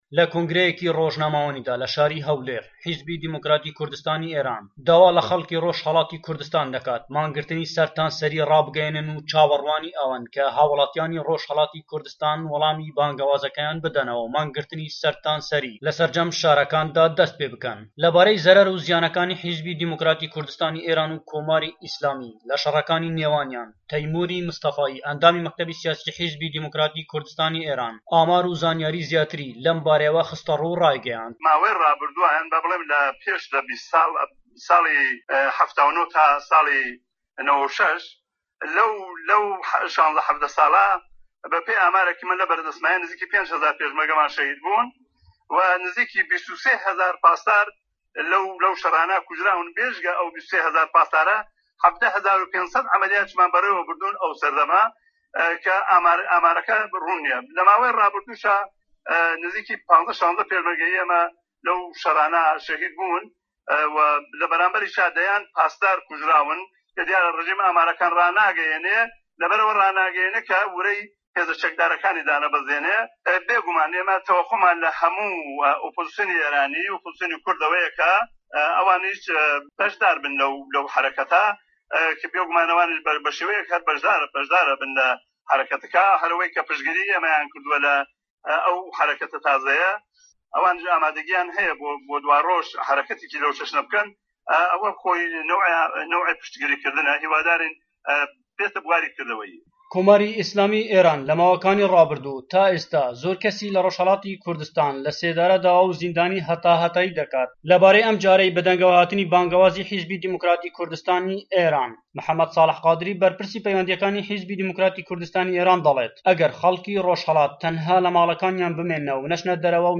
له‌ كۆنگره‌يه‌كى رۆژنامه‌وانيدا له‌ شارى هه‌ولێر حيزبى ديموكراتى كوردستانى ئێران داوا له‌ خه‌ڵكى رۆژهه‌ڵاتى كوردستان ده‌كات مانگگرتنى سه‌رتانسه‌رى رابگه‌يه‌نن و چاوەڕوانى ئه‌وه‌ن كه‌ هاوڵاتیانی ڕۆژهەڵاتى كوردستان وڵامی بانگەوازەکەیان بدەنەوە و مانگرتنی سەرتاسەری لەسەرجەم شارەکاندا دەستپێبکەن.